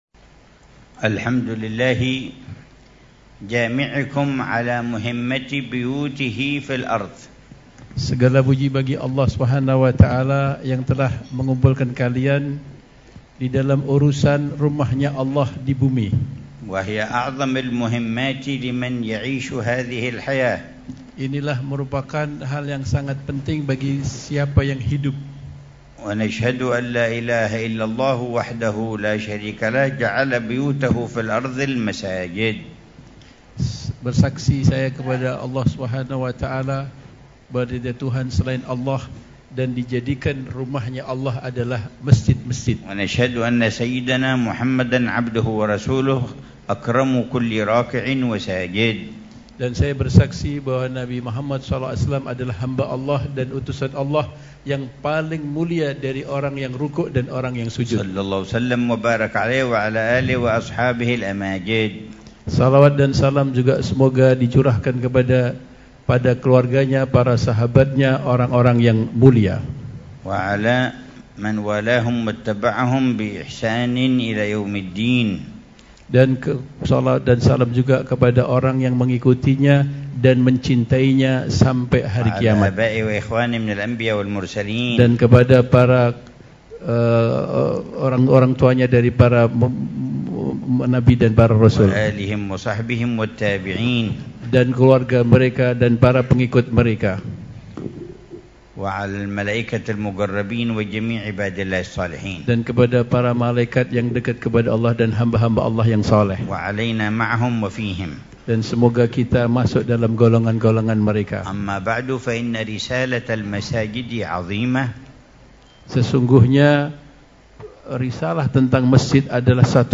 محاضرة في ملتقى نهضة مساجد إندونيسيا